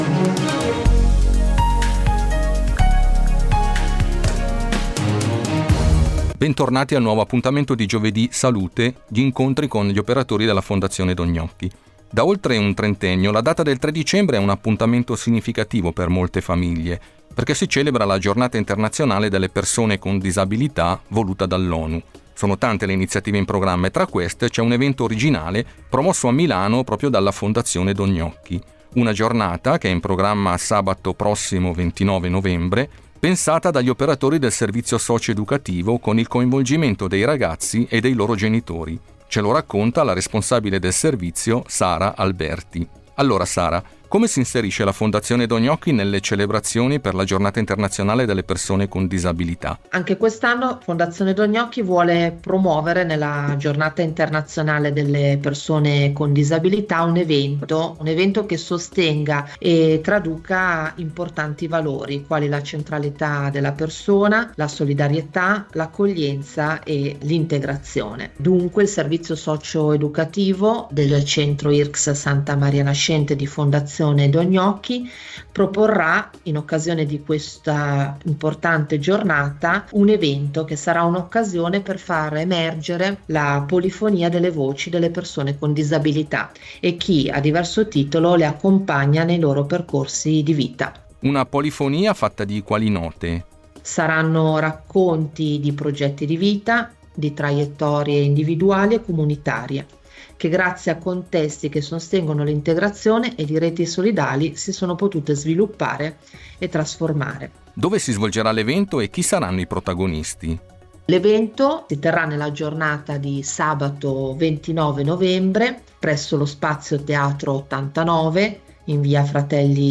(ascolta qui la sua intervista in radio), che hanno ricordato il valore dell’ascolto autentico e della narrazione come forma di cura.